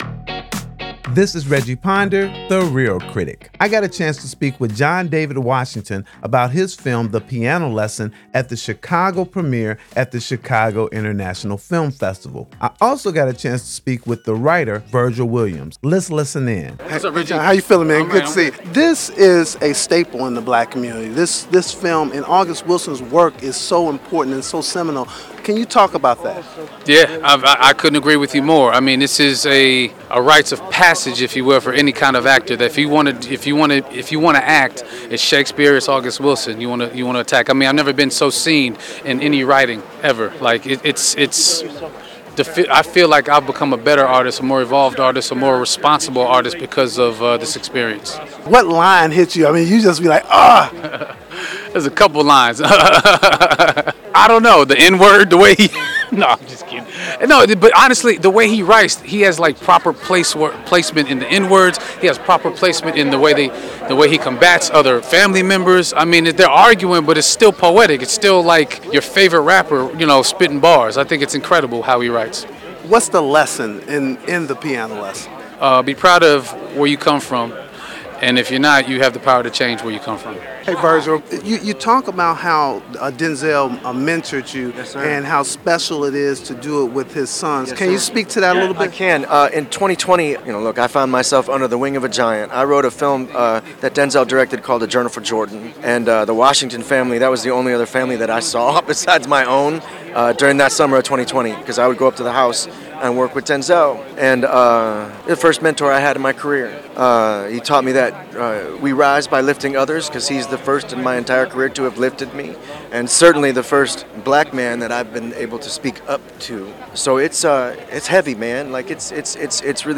Malcolm Washington directs a new adaptation of August Wilson’s 1987 play ‘The Piano Lesson,’ which was featured at the Chicago International Film Festival. On the red carpet at the festival before its premiere